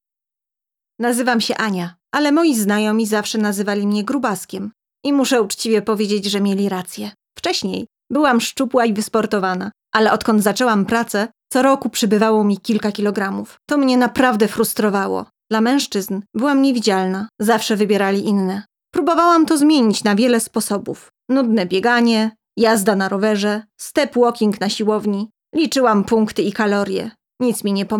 Lehçe Seslendirme
Kadın Ses